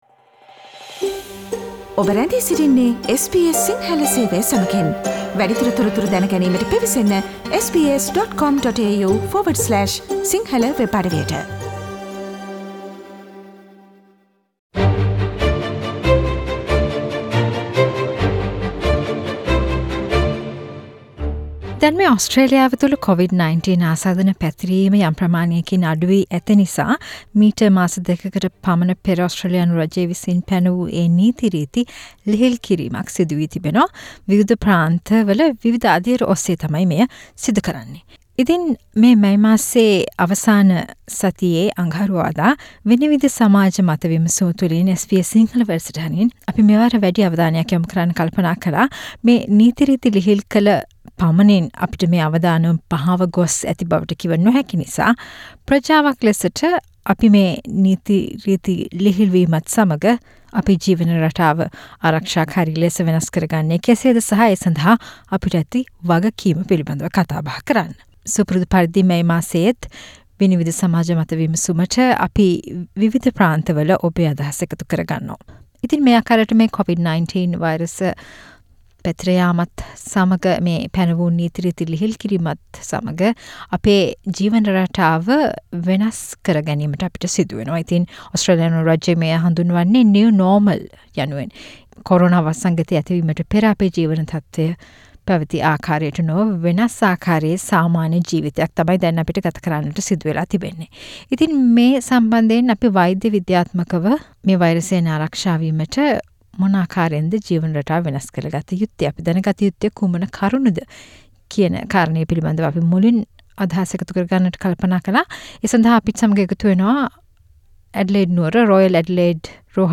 Vinivida monthly panel discussion focused on our community responsibility to be safe amid easing of coronavirus restrictions by states